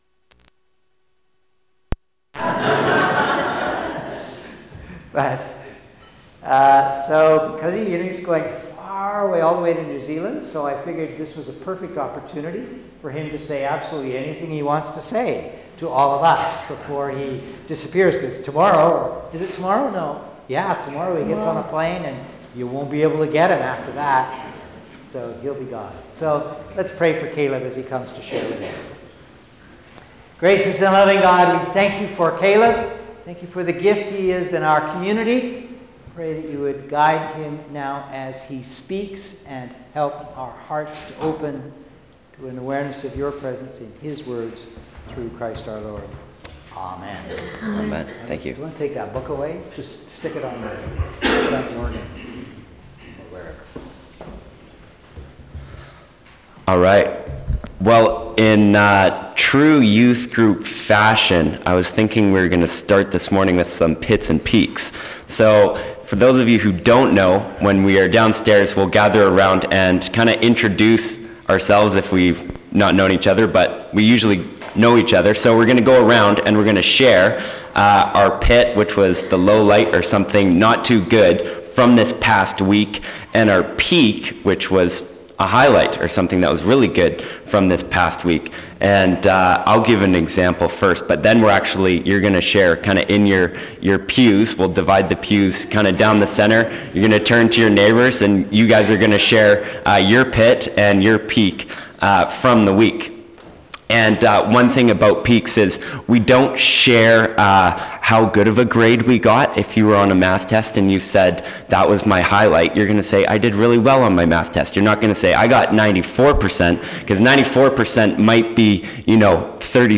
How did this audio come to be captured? This recording has been edited to remove the serveral minutes of "Pits and Peaks" discussion time amongst the congregation.